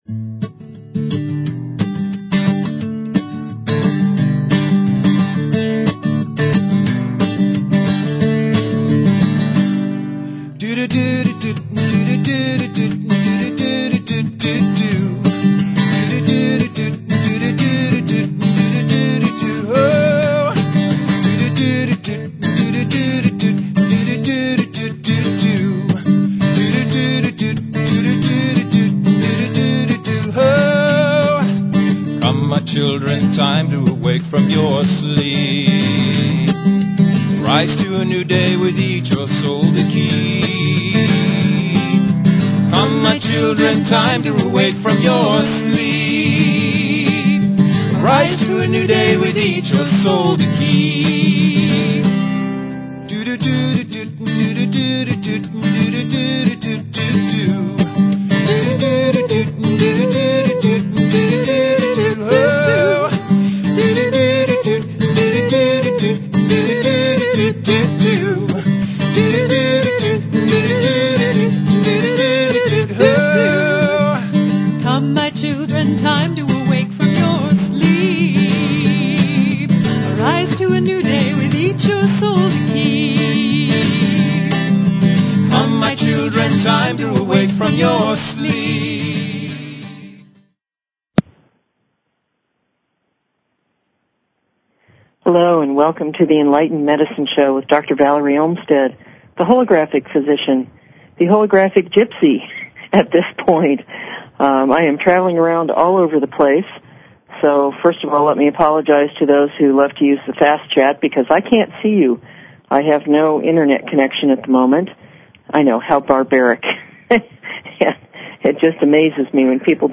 Talk Show Episode, Audio Podcast, Enlightened_Medicine and Courtesy of BBS Radio on , show guests , about , categorized as
Call in for free healings or to share your comments on giving or receiving healing energies.